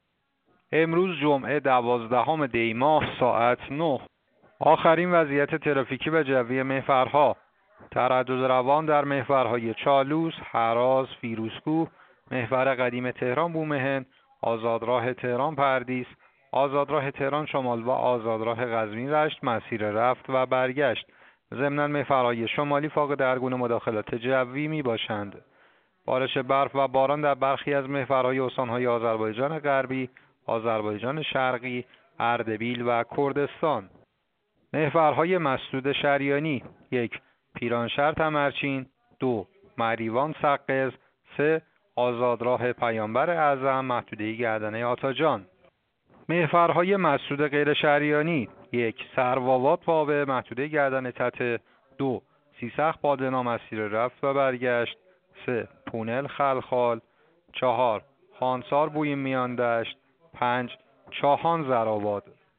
گزارش رادیو اینترنتی از آخرین وضعیت ترافیکی جاده‌ها ساعت ۹ دوازدهم دی؛